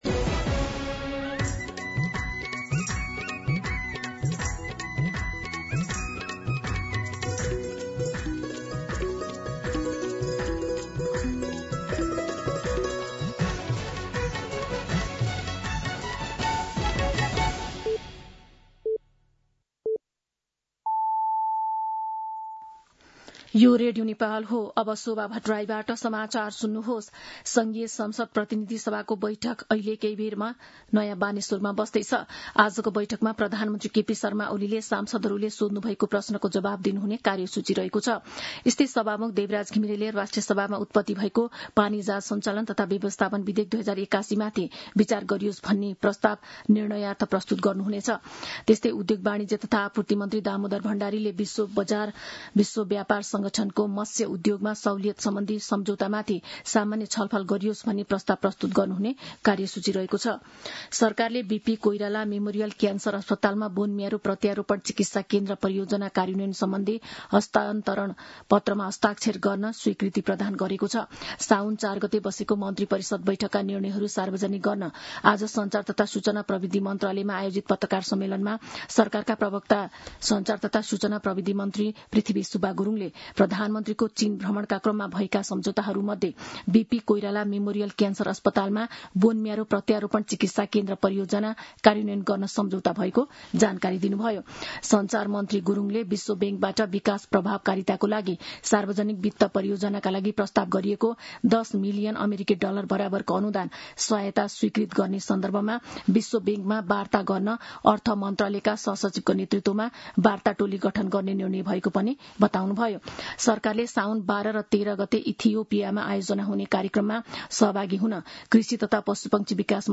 दिउँसो १ बजेको नेपाली समाचार : ७ साउन , २०८२
1pm-News-04-7.mp3